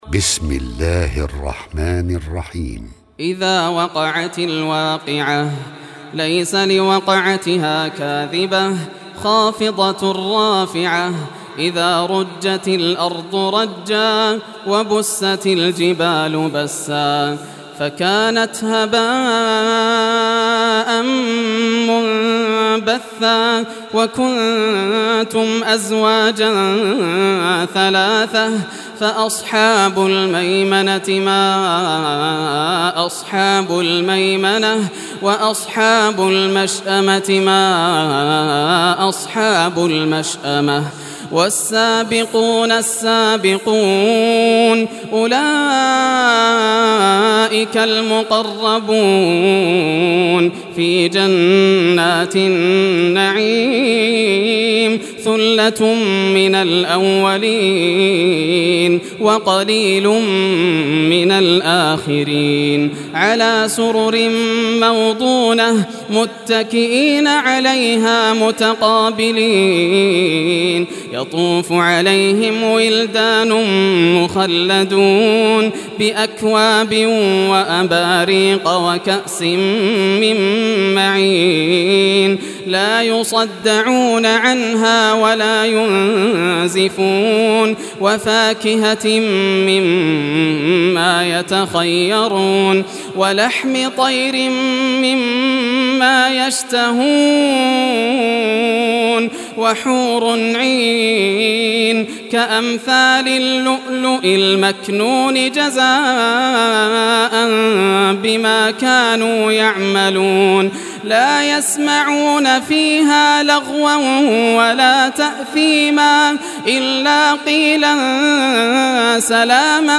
Surah Waqiah Recitation by Yasser al Dosari
Surah Waqiah, listen or play online mp3 tilawat / recitation in arabic in the beautiful voice of Sheikh Yasser al Dosari.